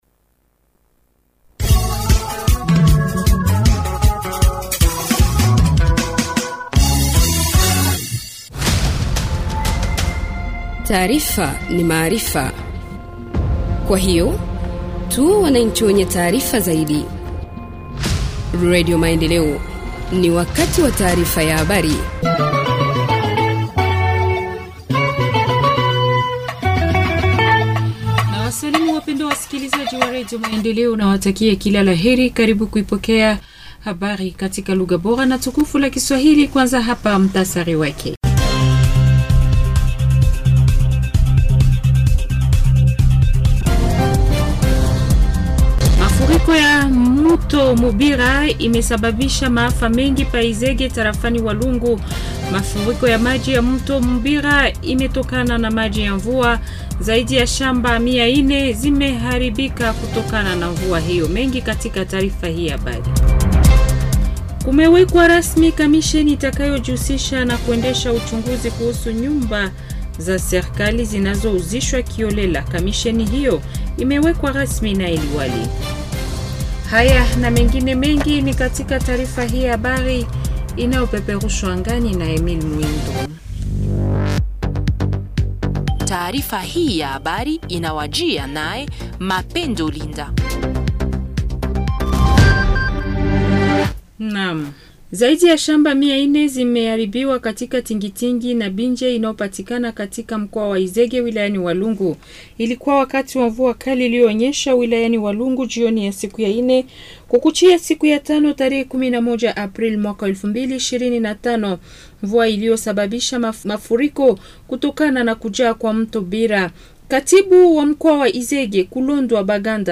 Journal en Swahili du 12 avril 2025 – Radio Maendeleo